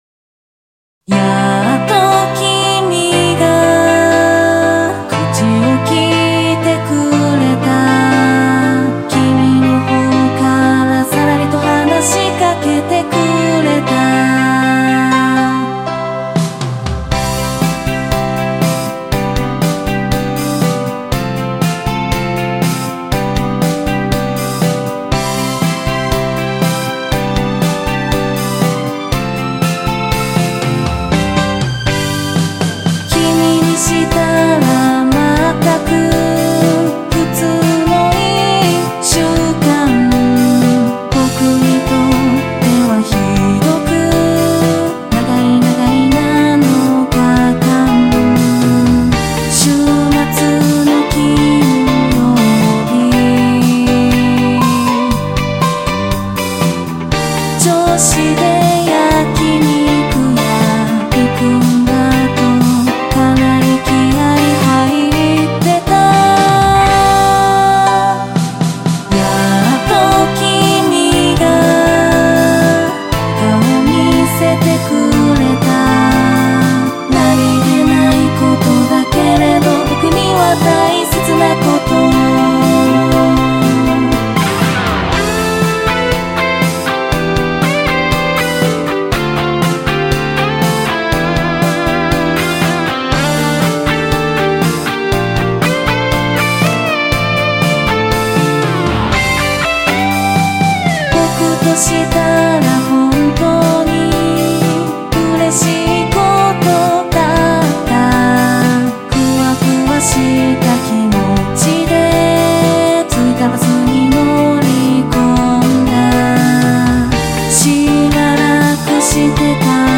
演奏時間 3:14 制作音源 Roland INTEGRA-7、SC-8850　YAMAHA MU1000EX
遊びを入れずにシンプルに仕上げました。